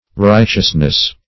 Righteousness \Right"eous*ness\, n. [AS. rihtw[imac]snes.]